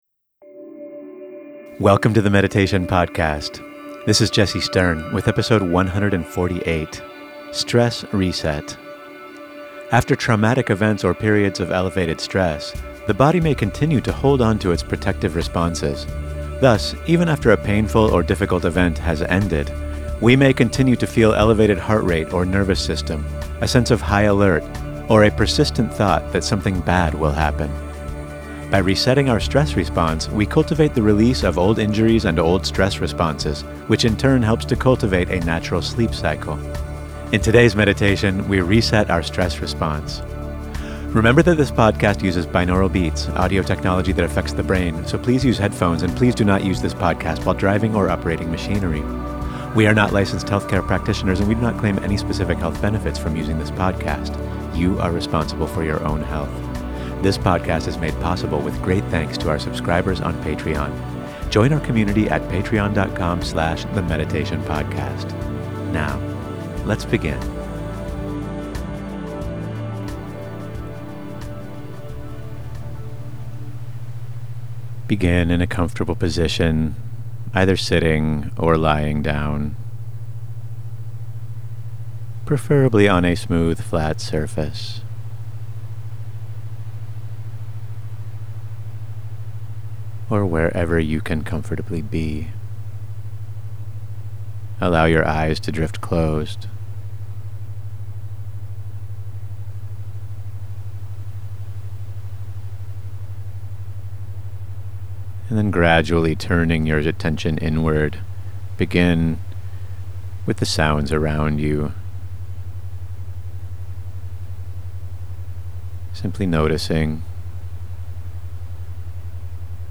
By resetting our stress response, we cultivate the release of old injuries and old stress responses, which in turn helps to cultivate a natural sleep cycle. In today's meditation, we reset our stress response.